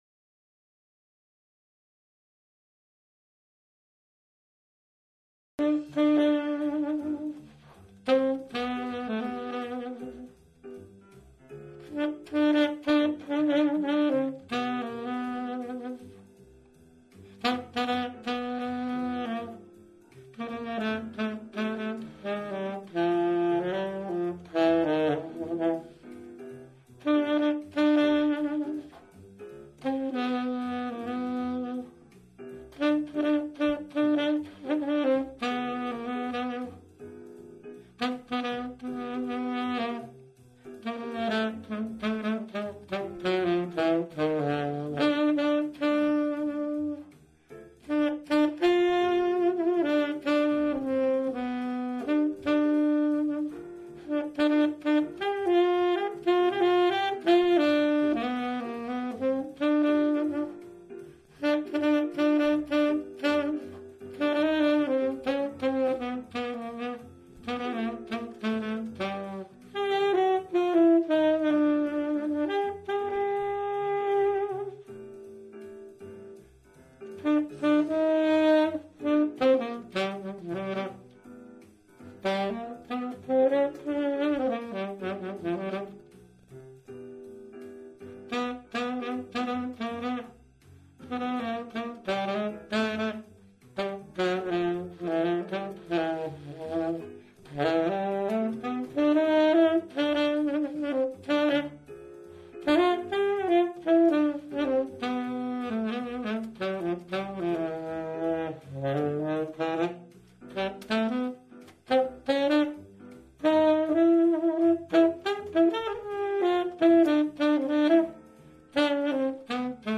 TENOR SAX AUDIO RECORDINGS !
All the tenor tunes recorded in Toulouse (France)